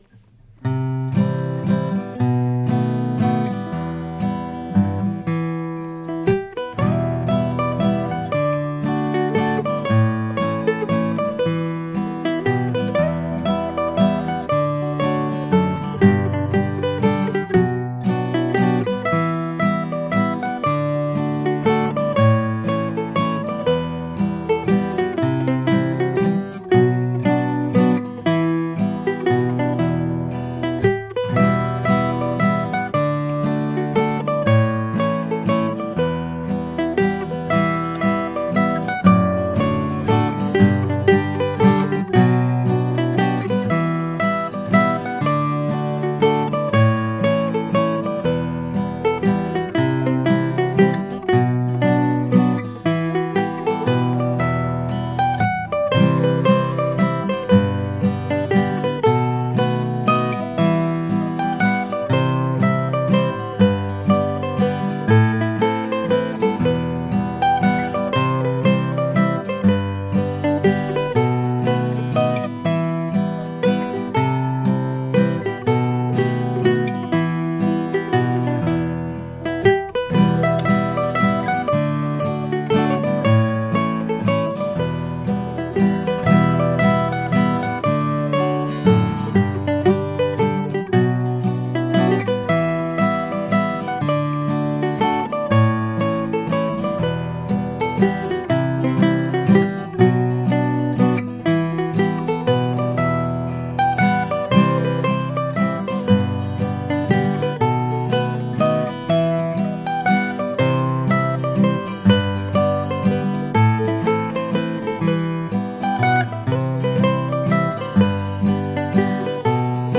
Recorded yesterday using a Martin 00-18V and my old Gibson A model mandolin.